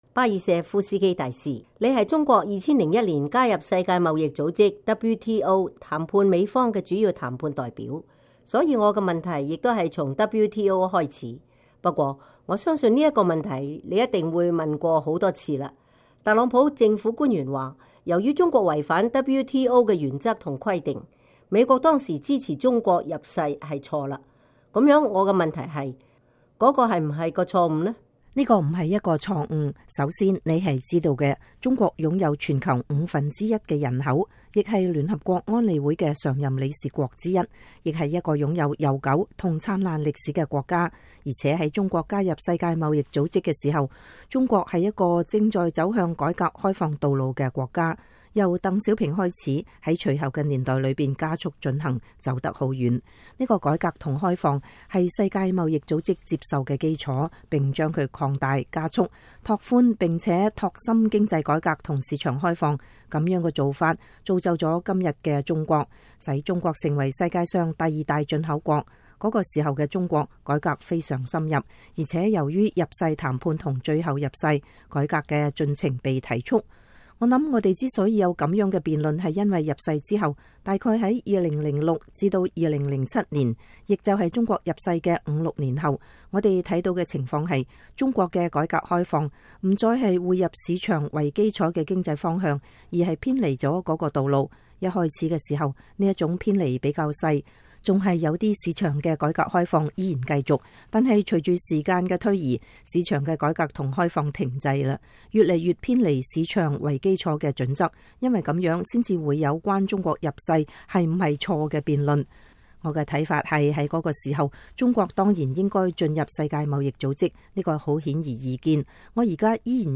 專訪巴爾舍夫斯基 - 美中分手結果可怕